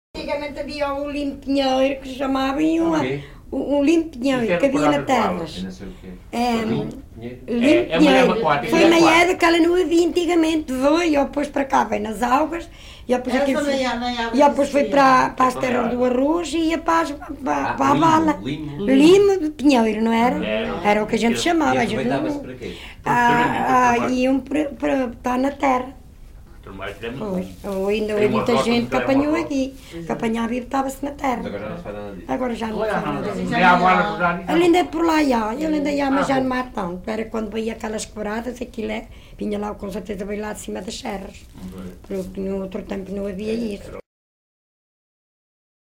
LocalidadeVila Pouca do Campo (Coimbra, Coimbra)